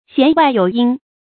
弦外有音 注音： ㄒㄧㄢˊ ㄨㄞˋ ㄧㄡˇ ㄧㄣ 讀音讀法： 意思解釋： 弦樂器的弦上發出的聲音以外還有聲音。